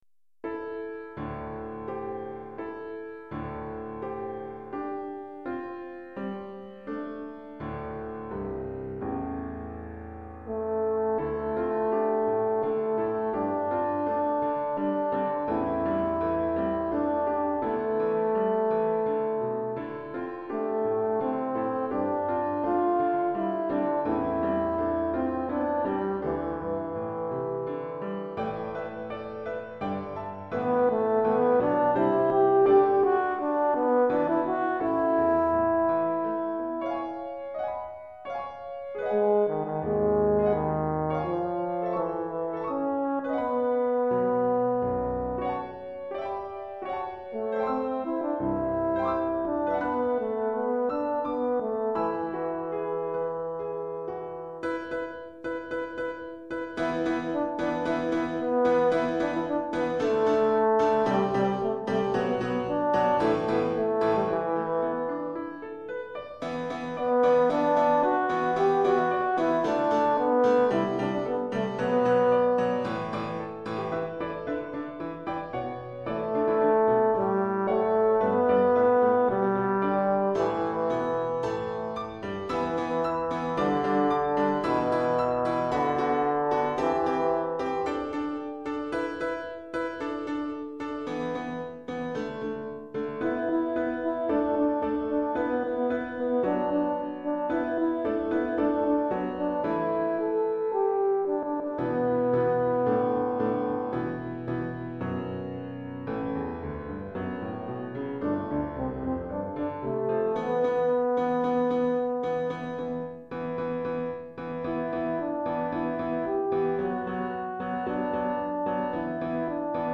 Cor en Fa et Piano